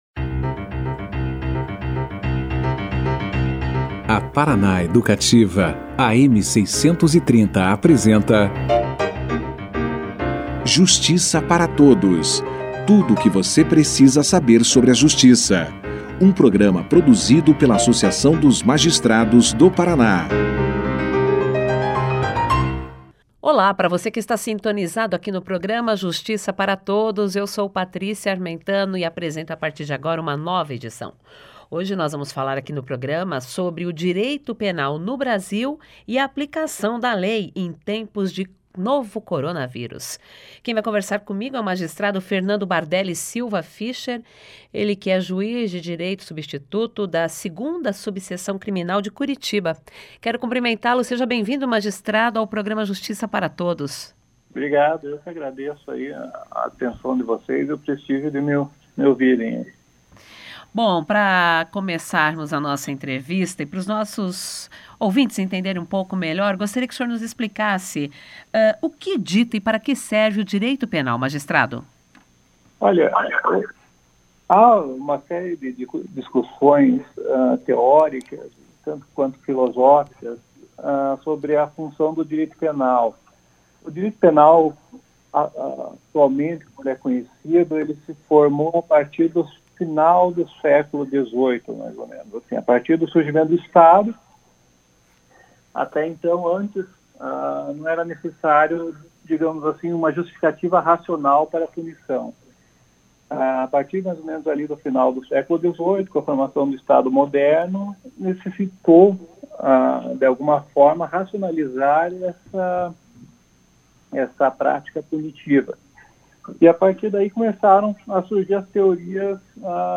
>>Clique Aqui e Confira a Entrevista na Integra<<
O Programa Justiça Para Todos entrevistou o Juiz Substituto da 2º subseção criminal de Curitiba, Fernando Fischer, para falar sobre a determinação da Lei Penal, que regula o exercício do poder punitivo do Estado, em ações de delitos. O magistrado explicou o que um juiz deve considerar para aplicar a pena, como é feito o cálculo dessa pena e, diante do avanço do cenário da pandemia do novo coronavírus, como está a aplicação da Lei no país na defesa dos interesses das pessoas e das instituições.